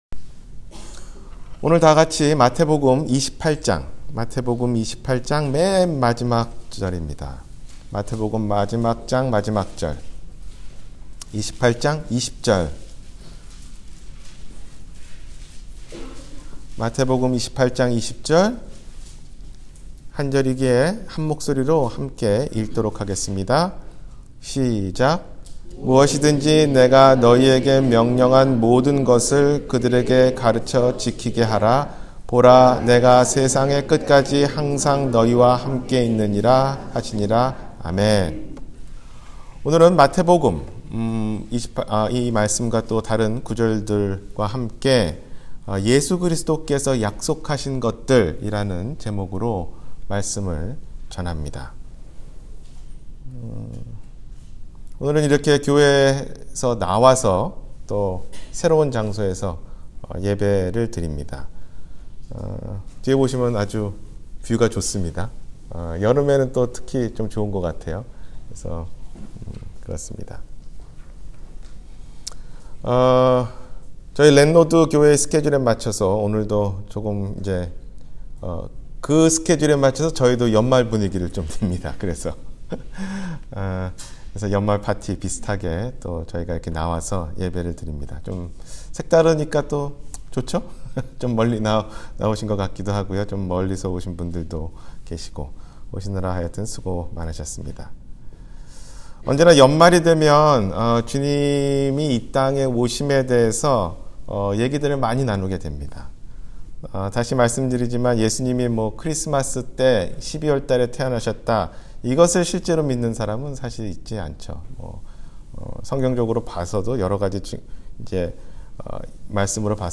예수 그리스도의 약속 – 주일설교